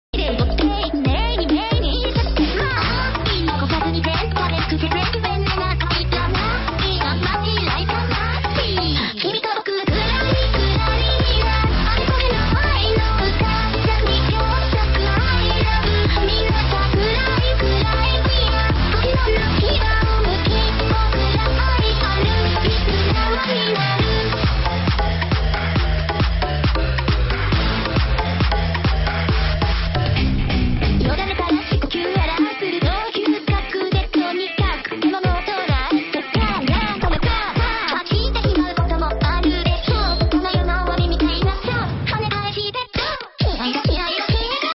涉及术力口本家和泛术力口歌姬